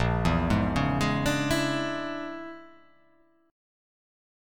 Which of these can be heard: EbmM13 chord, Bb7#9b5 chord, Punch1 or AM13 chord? Bb7#9b5 chord